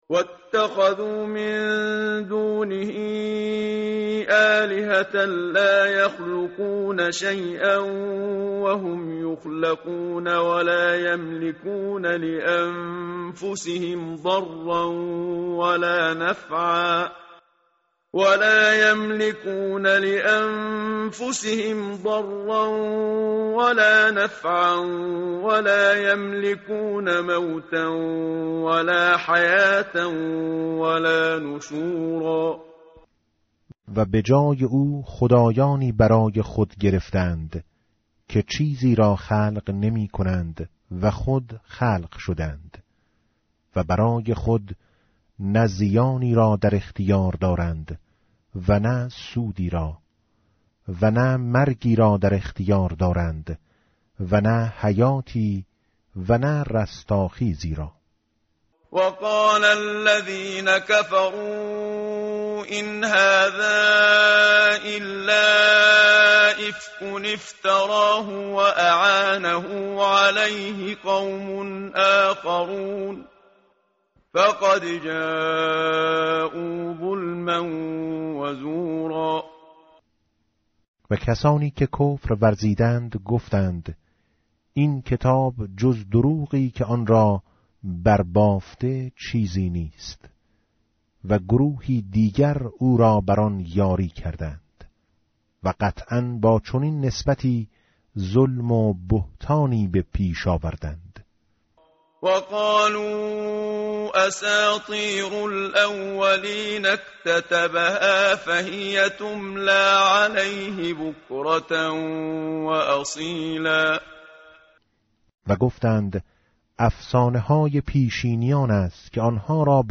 tartil_menshavi va tarjome_Page_360.mp3